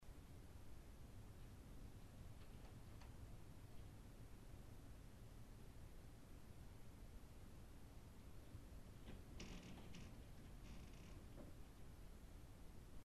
Child’s Room – 6:56 pm
Footsteps while no one was in the house